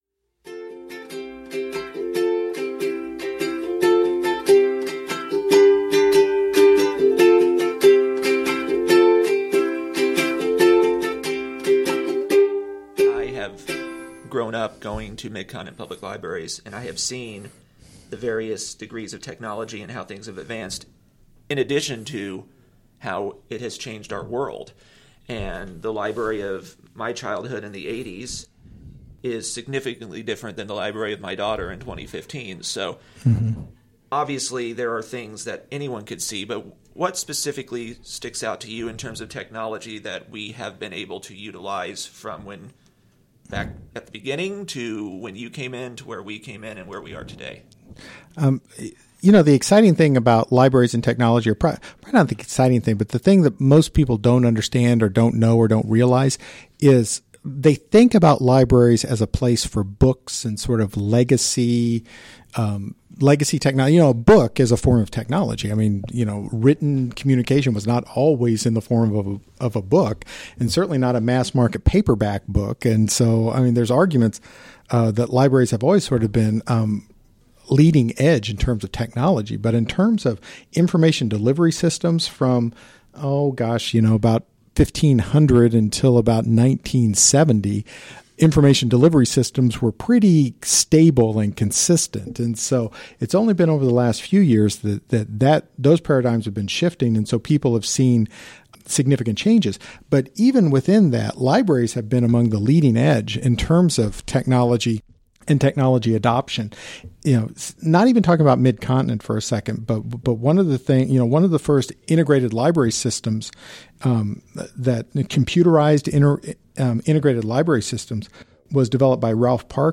For its 50th anniversary, a series of interviews were recorded detailing the story of Mid-Continent Public Library.